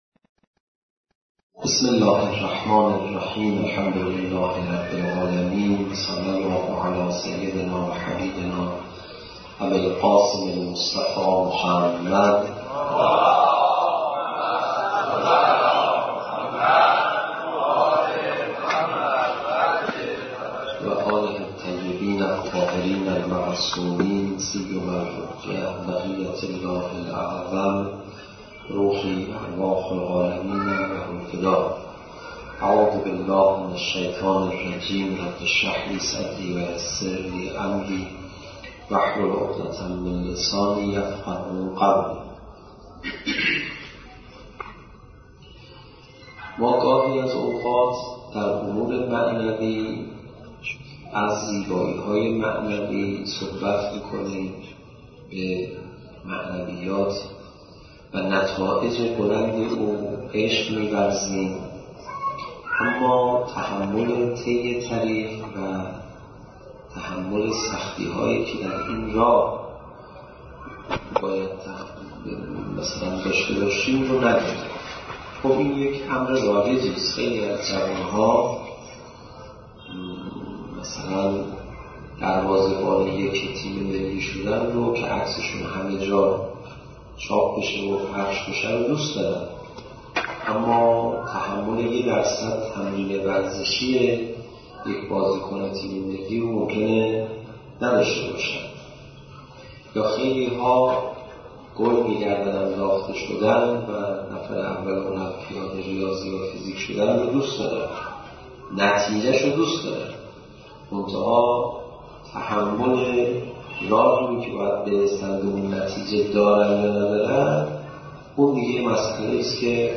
سخنرانی : حجت الاسلام علیرضا پناهیان با موضوع ((علت ازدواج نکردن حضرت معصومه س))